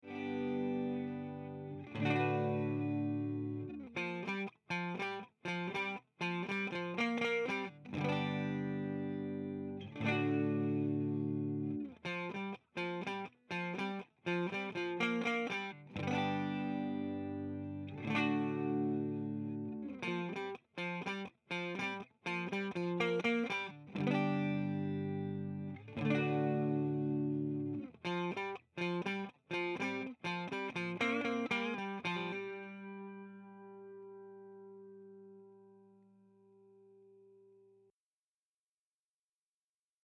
Ich habe unseren Probanden zwischen eine Paula-Kopie mit meinen heißgeliebten Fat Cats, einen Marshall und eine 4x12er-Box geklemmt.
Clean | Originalsignal
tc_electronic_sub_n_up_octaver_testbericht_gitarre_clean.mp3